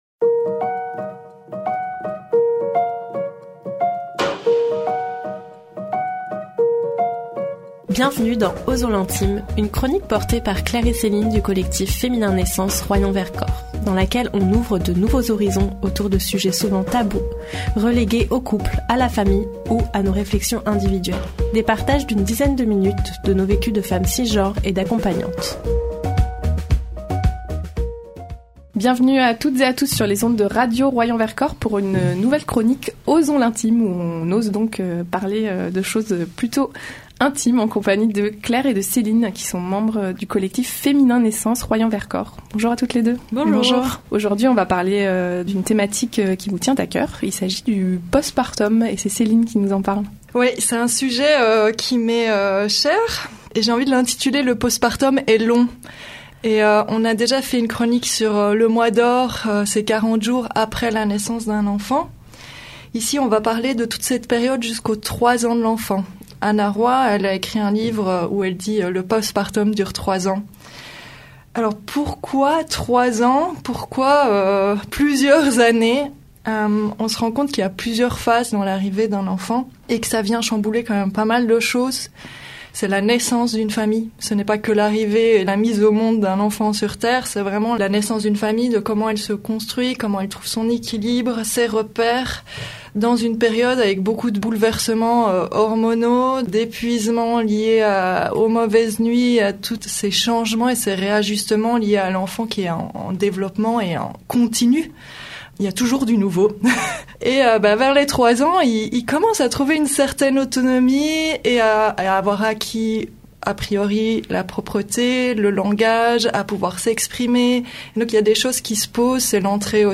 Une nouvelle chronique à découvrir sur les ondes de Radio Royans Vercors : Osons l’intime !